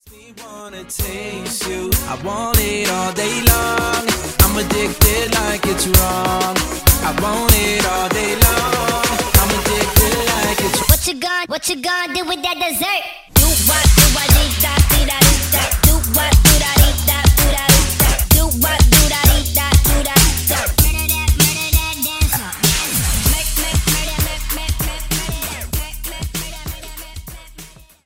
Dj Intro Outro – Get Yours Now & Add To Cart